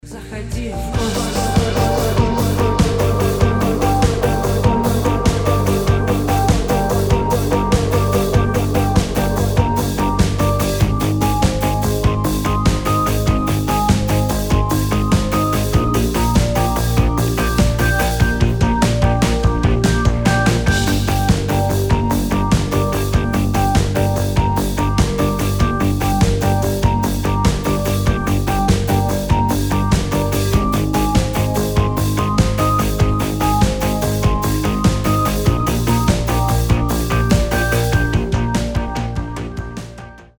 атмосферные
космические
indie pop
experimental
Необычная электронная инди музыка